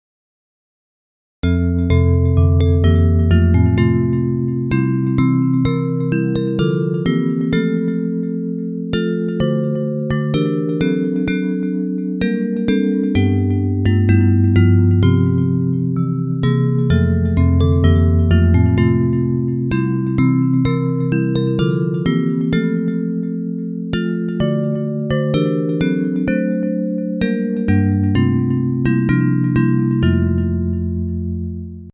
Bells Version